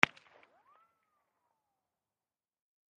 Mac-10 Machine Gun Single Shot From Distant Point of View, X3